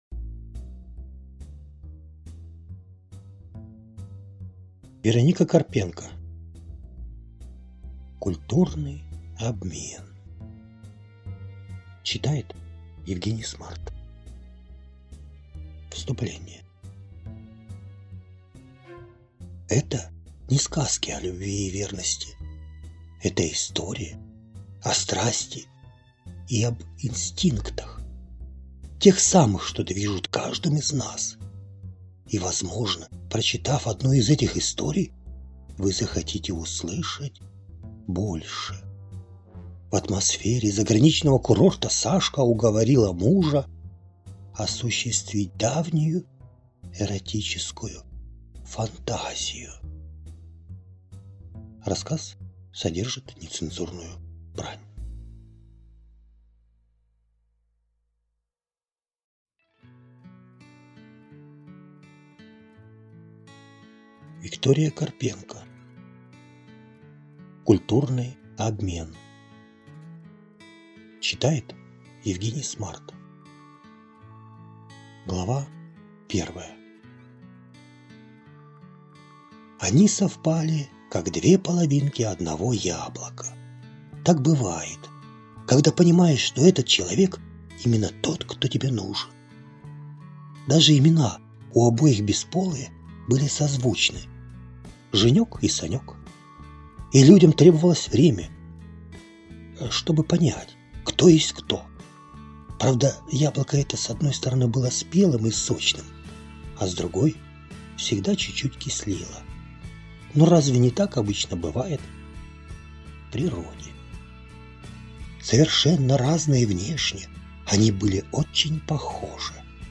Аудиокнига Культурный обмен | Библиотека аудиокниг
Прослушать и бесплатно скачать фрагмент аудиокниги